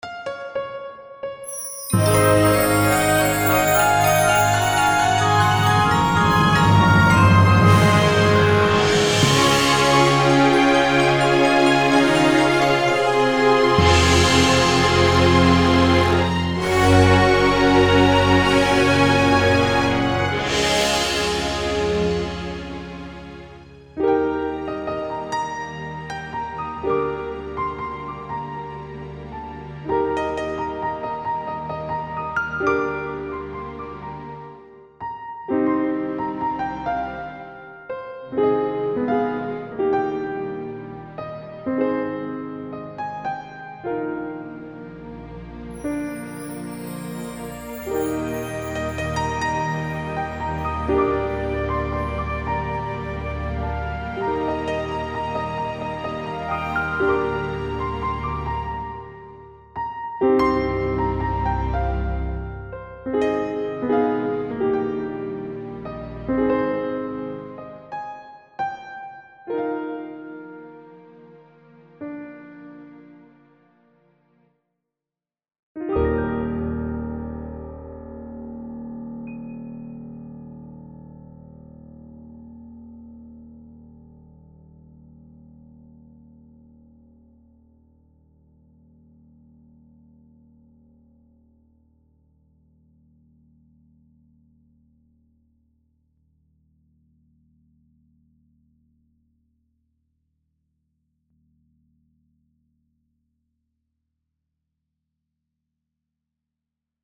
Voilà la fin remodelée avec de la nuance et de l'humanisation
J'en ai profité pour ajouter un petit glissando de flûte à la fin de la montée en puissance (0'06) pour ajouter un côté magique et un glissando de harpe (0'19) pour la même raison.